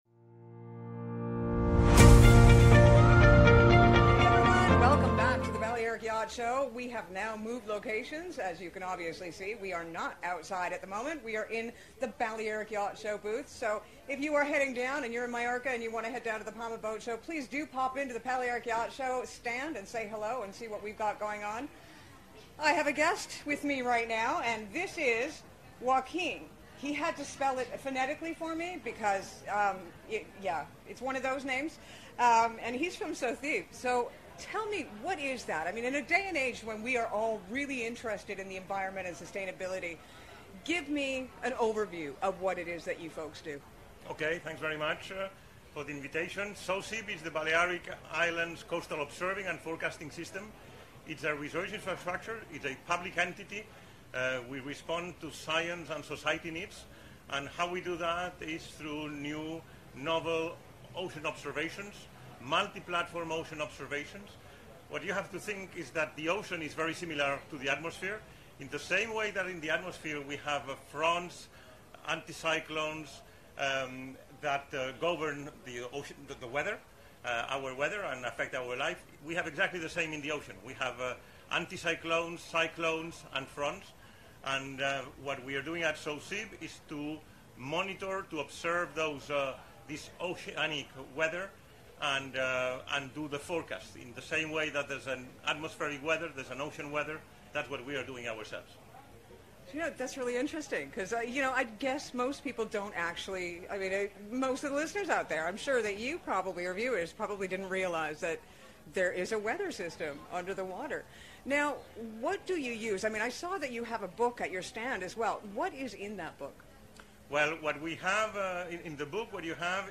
If you missed some of the great interviews that were had during The Balearic Yacht Show, not to worry, we will be bringing you a few of them here!